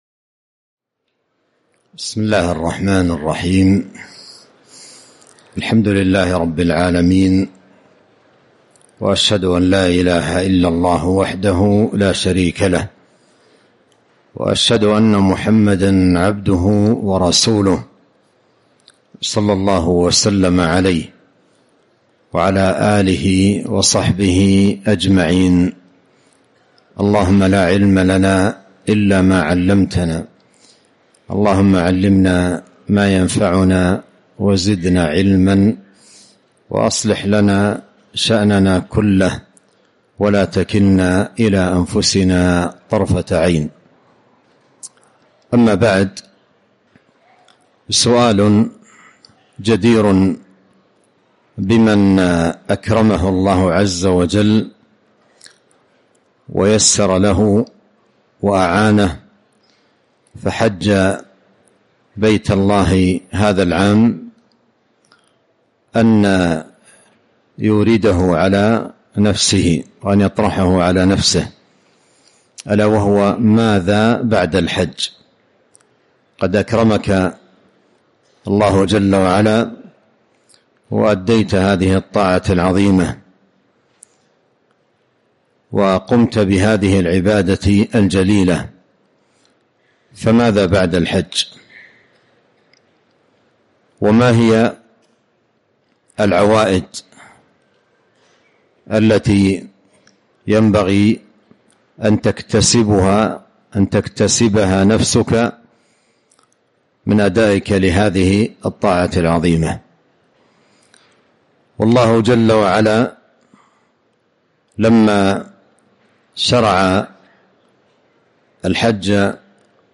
محاضرة - ماذا بعد الحج ؟